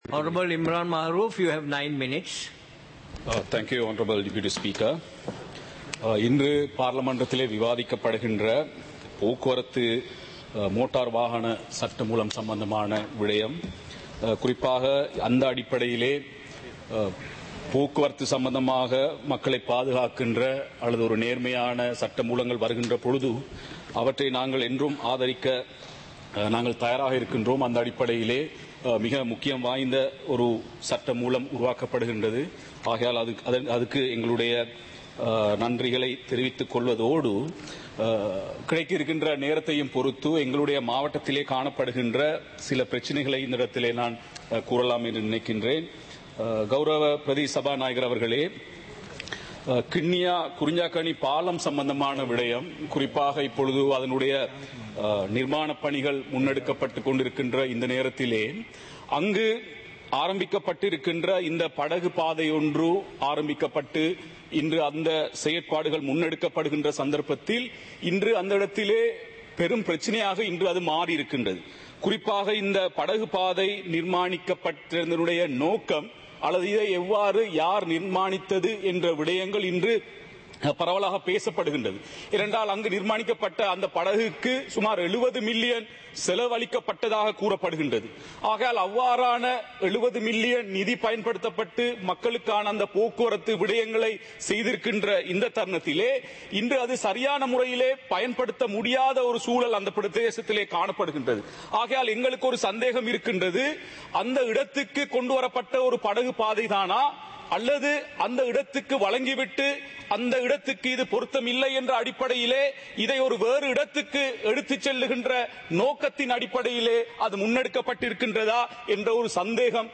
சபை நடவடிக்கைமுறை (2026-01-08)
பாராளுமன்ற நடப்பு - பதிவுருத்தப்பட்ட